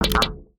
UIClick_Menu Reject Single 02.wav